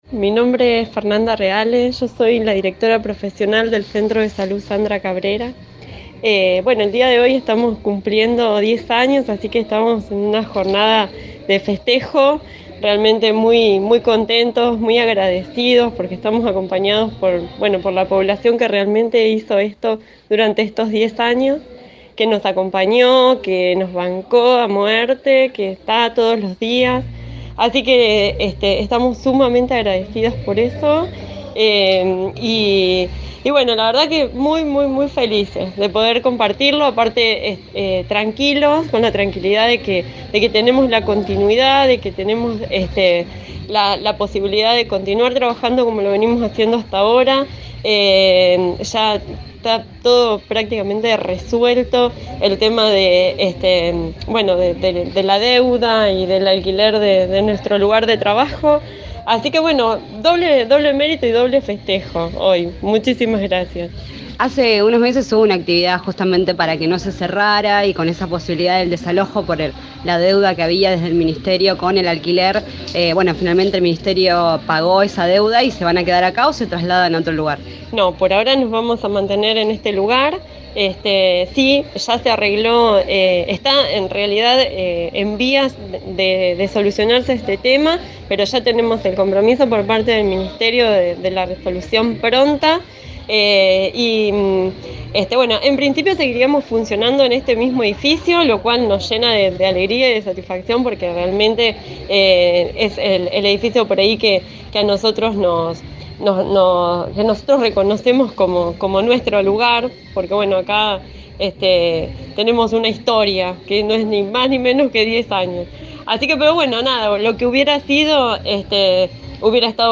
En diálogo con el móvil de Radio Estación Sur, respecto a la situación por la que atravesó el Centro de Salud hace unos meses cuando, ante la deuda por el pago del alquiler, se temía el desalojo del espacio manifestó: «Está en vías de solucionarse este tema, pero ya tenemos el compromiso por parte del Ministerio de Salud.